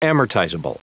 Prononciation du mot : amortizable